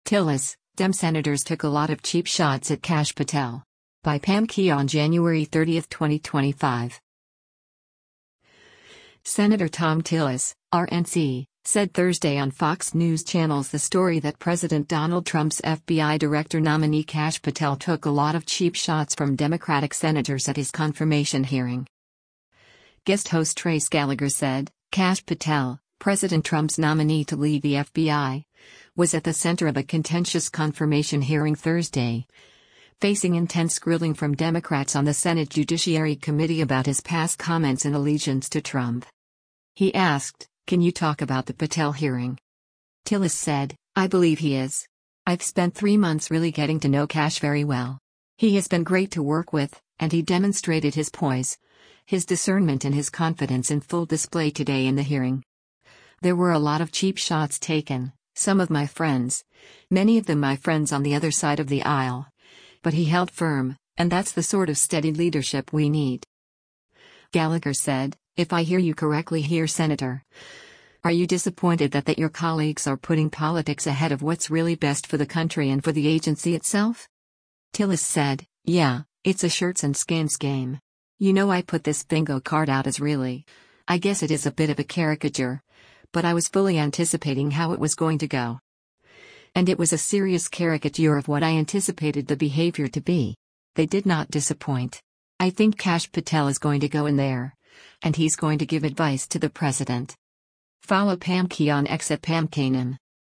Senator Thom Tillis (R-NC) said Thursday on Fox News Channel’s “The Story” that President Donald Trump’s FBI director nominee Kash Patel took a lot of “cheap shots” from Democratic Senators at his confirmation hearing.